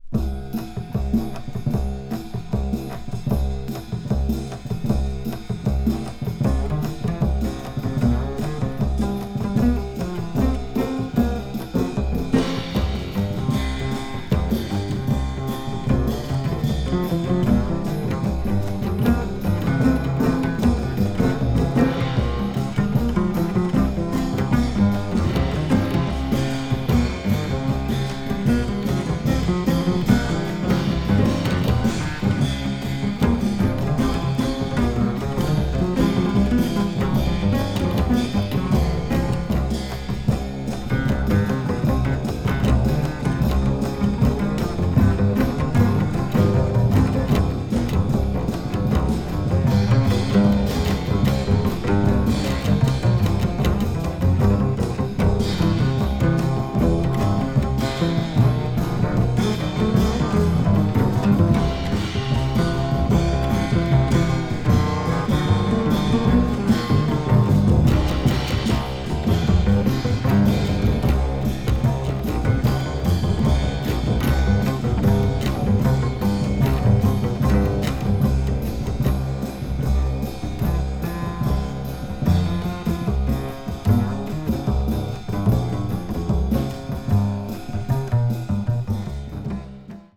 media : EX/EX(わずかにチリノイズが入る箇所あり)
avant-jazz   contemporary jazz   deep jazz   free jazz